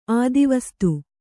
♪ ādivastu